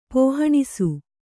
♪ pōhaṇisu